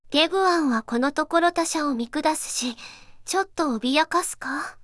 voicevox-voice-corpus
voicevox-voice-corpus / ROHAN-corpus /四国めたん_セクシー /ROHAN4600_0002.wav